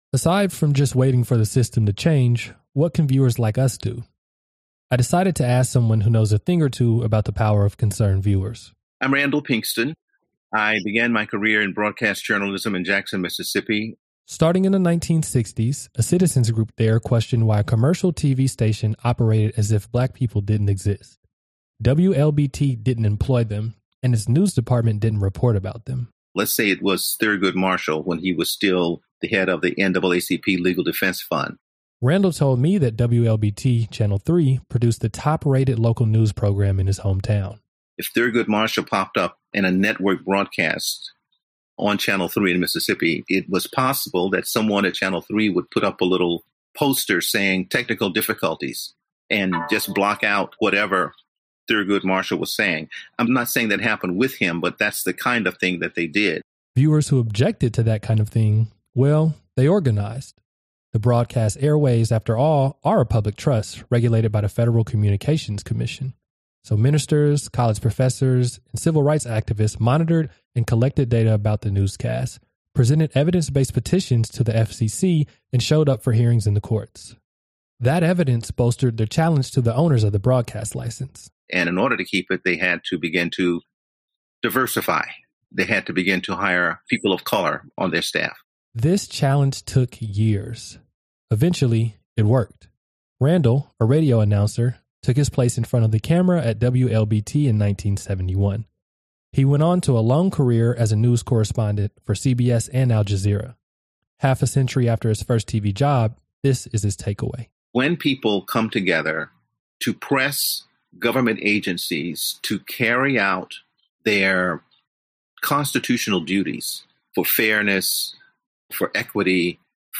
Interview Excerpt